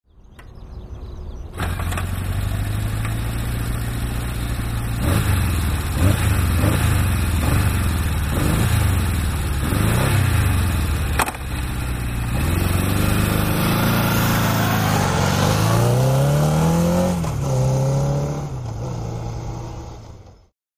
今では珍しくはなくなった4気筒エンジン。
キック始動→発進の音声サンプル(MP3)
エンジン: 空冷/4ストローク/SOHC/2バルブ/4気筒